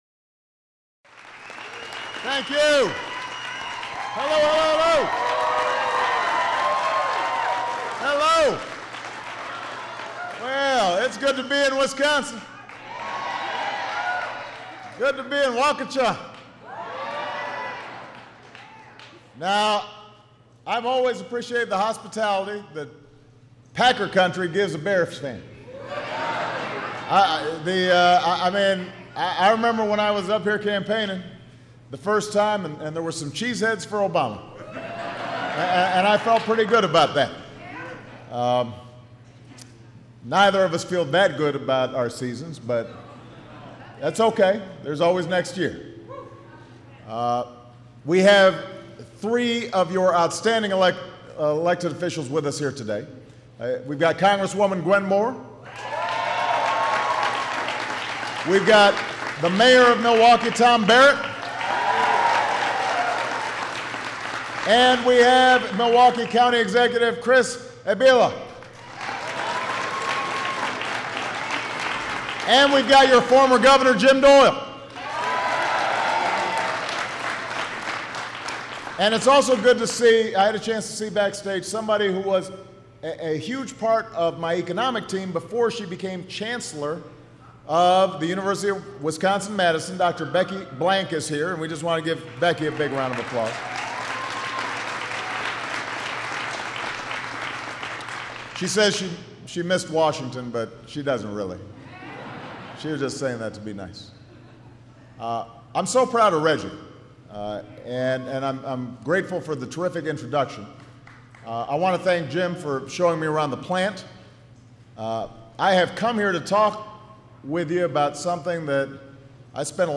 U.S. President Barack Obama delivers remarks on jobs and the economy